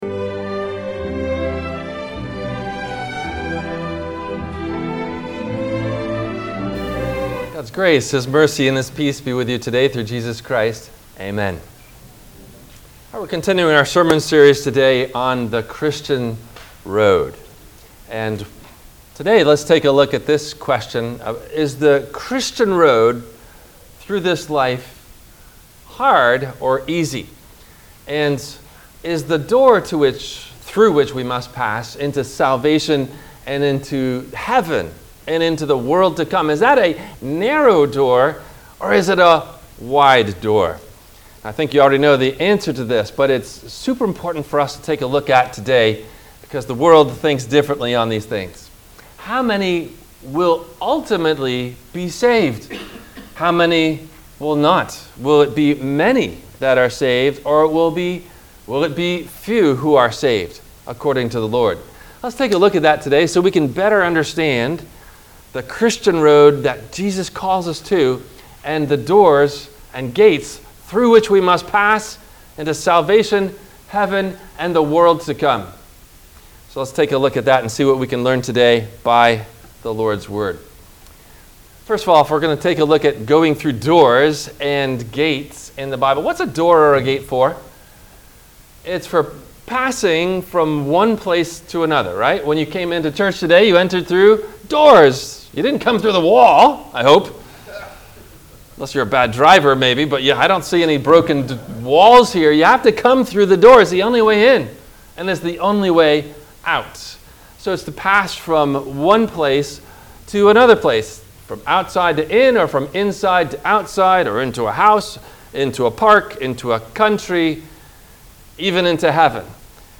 The Road of The Christian – Broad or Narrow? – WMIE Radio Sermon – April 01 2024